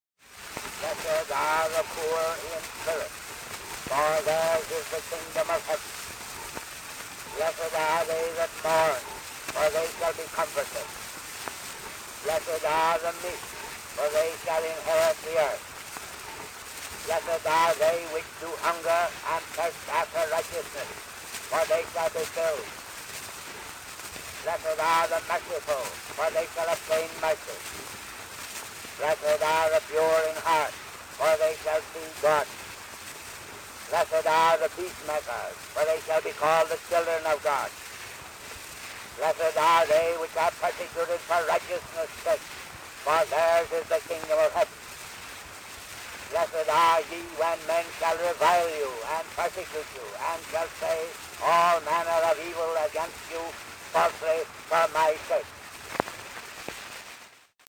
Reverend Thomas De Witt Talmage reads the Beatitudes